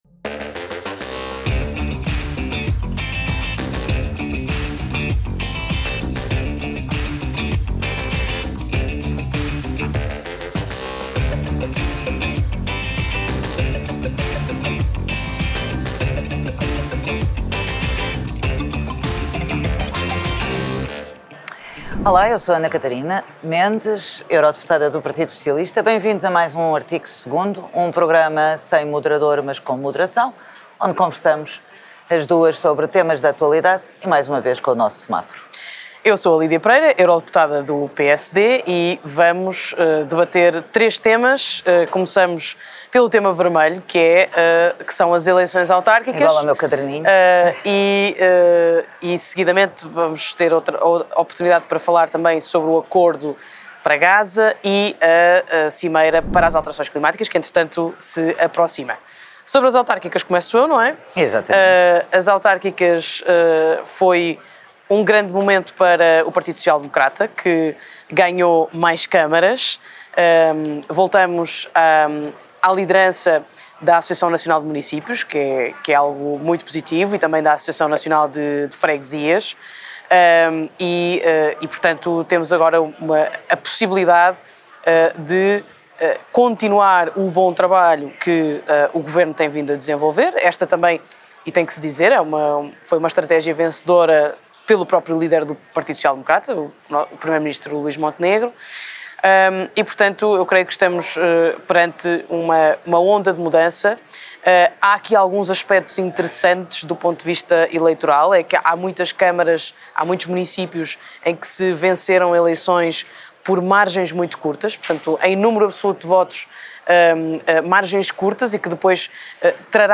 Continuamos, todos os meses, a debater em menos de 30 minutos, três temas na ordem do dia: Um em que discordamos.